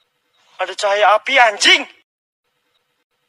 Efek suara Ada Cahaya Api Anjg
Kategori: Suara viral
Keterangan: Ungkapan ini menggambarkan situasi kaget atau dramatis dengan cara yang berlebihan, menciptakan momen lucu yang mengejutkan dan menghibur di berbagai video yang sedang tren.